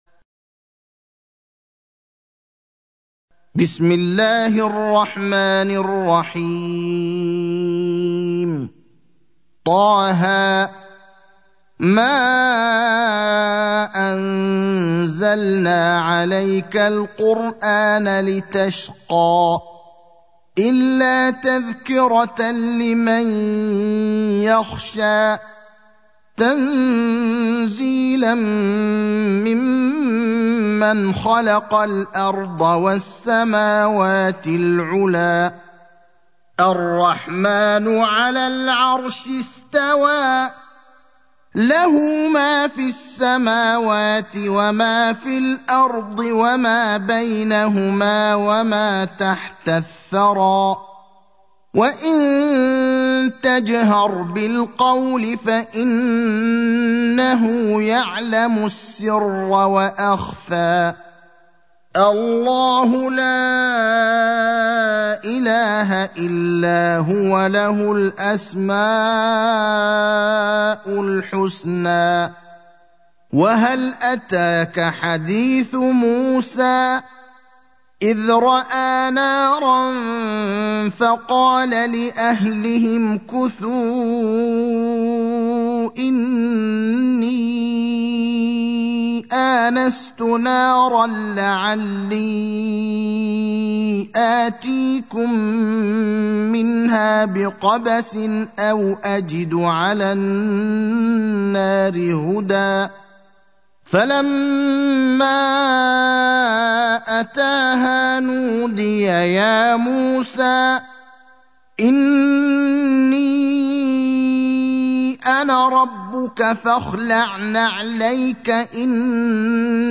المكان: المسجد النبوي الشيخ: فضيلة الشيخ إبراهيم الأخضر فضيلة الشيخ إبراهيم الأخضر طه (20)